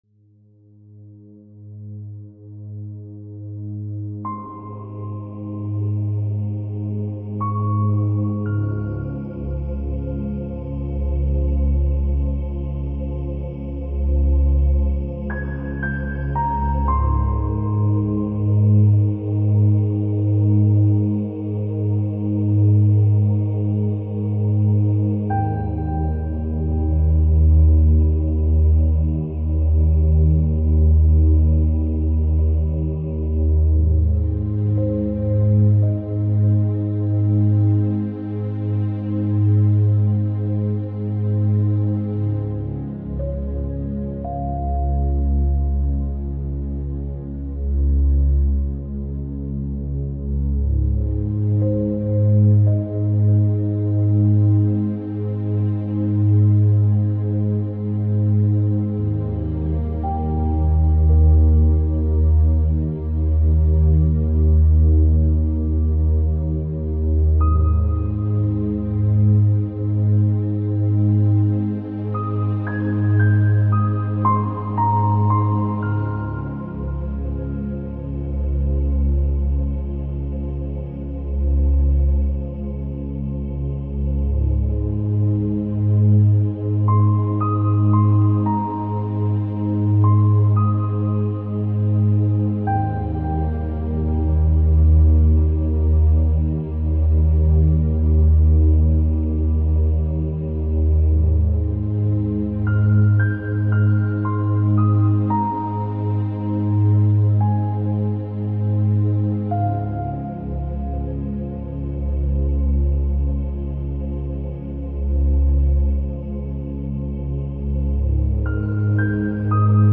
Une heure de musique de sommeil profond et relaxation totale